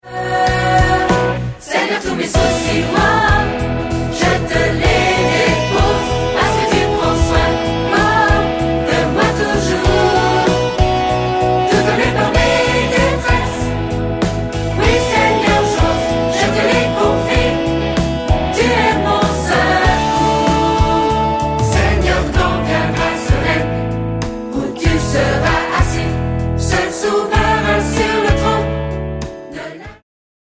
• Chants de louange pour églises, familles et autres
• Chorale et solistes